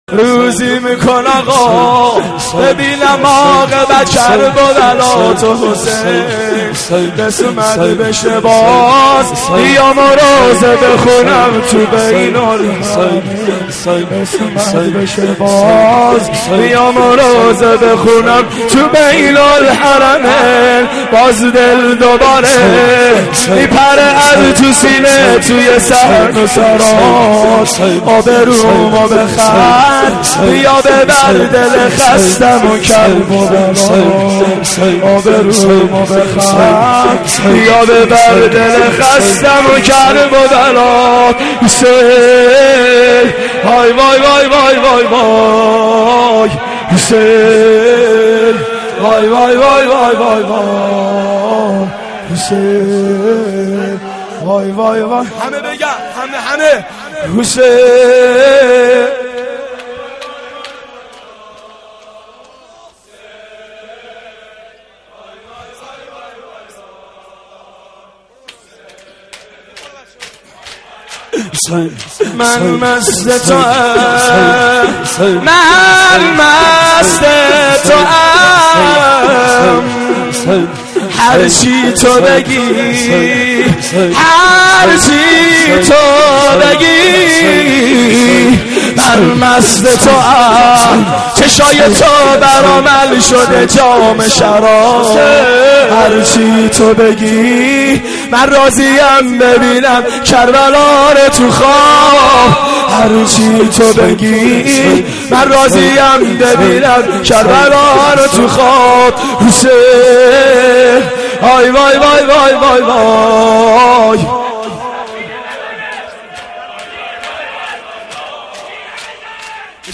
(شور - امام حسین علیه السلام)